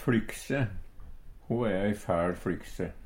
fLykse - Numedalsmål (en-US)
DIALEKTORD PÅ NORMERT NORSK fLykse lett på tråden Ubunde han-/hokj.